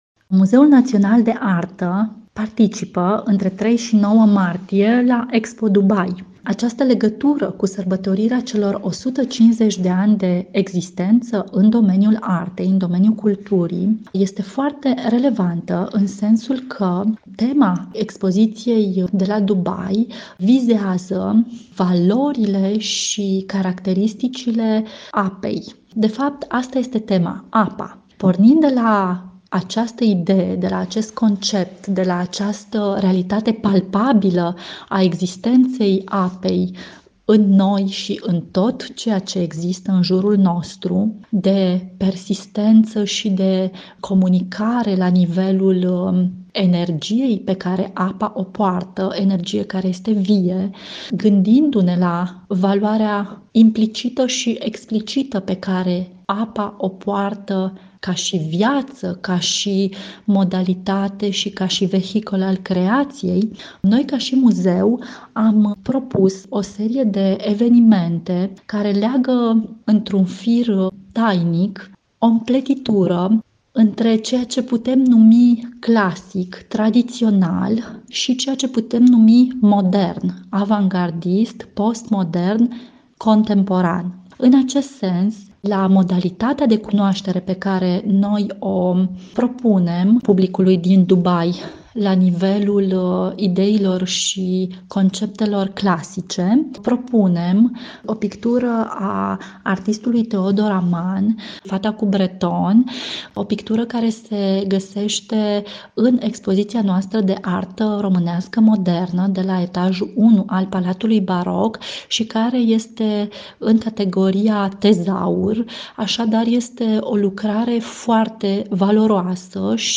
Interviu-fragment-02.mp3